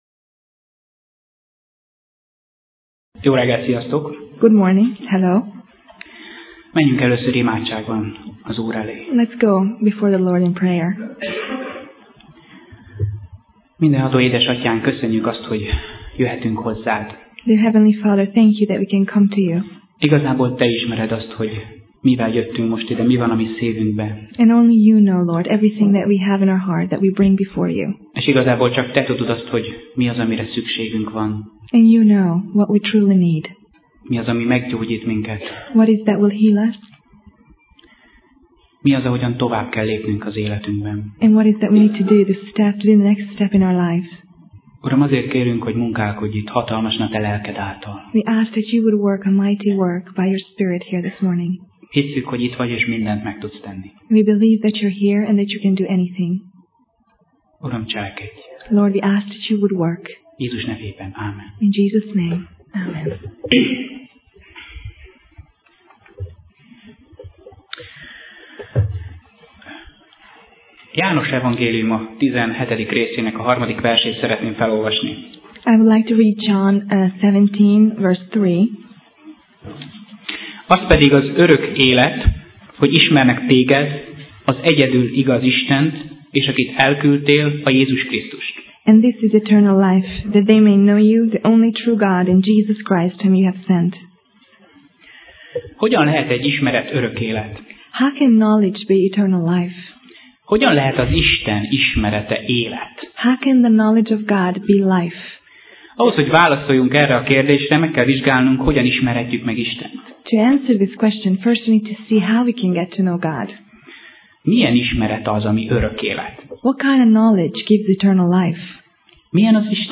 Sorozat: Tematikus tanítás
Alkalom: Vasárnap Reggel